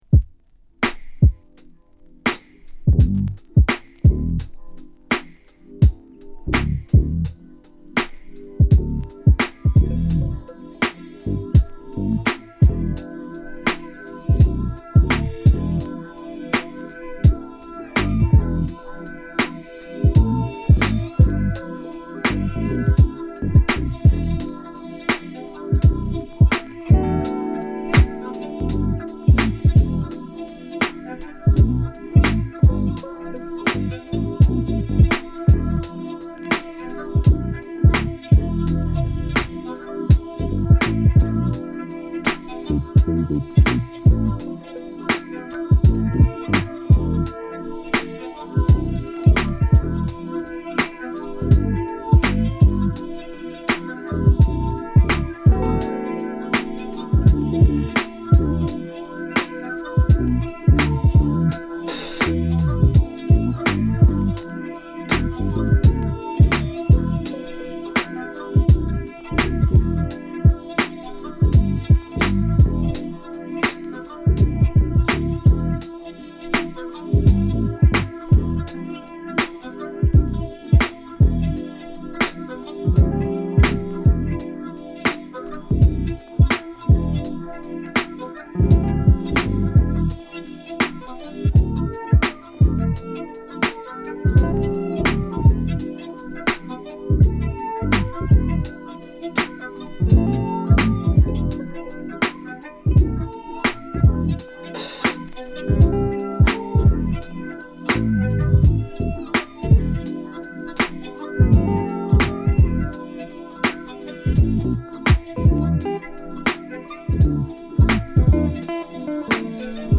Deep House
Mellow Groove